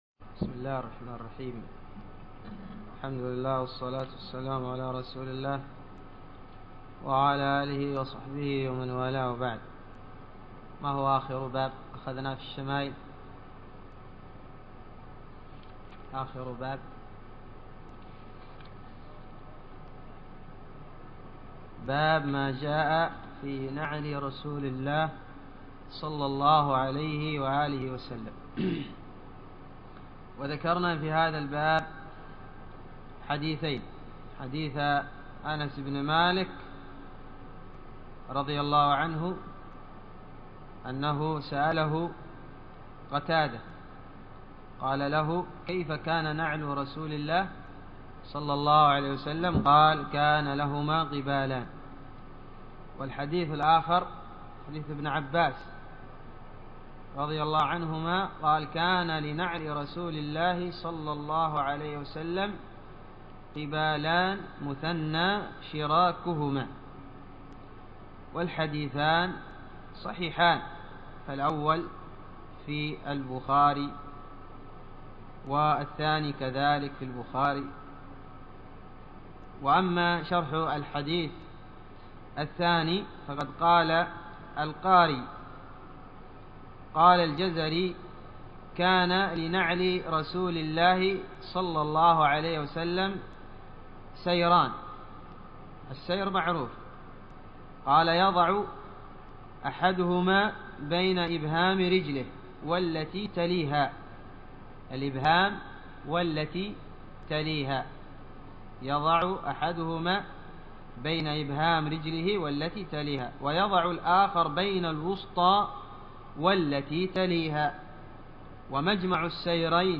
الدرس السابع عشر من دروس الشمائل المحمدية